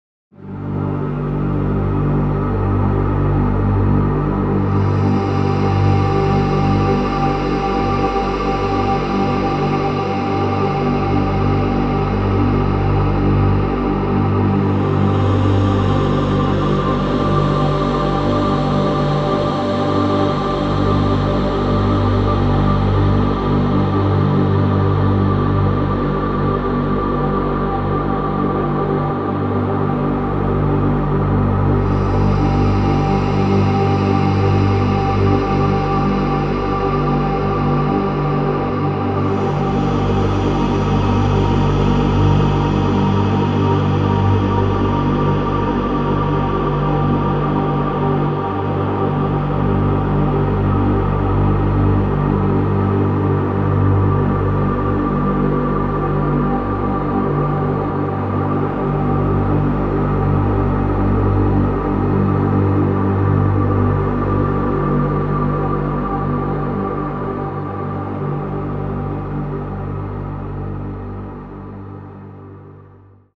Dark cinematic sound design instrument
• Six-layered sonic landscapes full of tension, chaos, and beauty
From eerie radio transmissions, to haunting synthesized atmospheres, to industrial clatter and military signals, It features a broad range of experimental audio samples.
Dystopia_Diary_-_Floating_High_Ambiences.mp3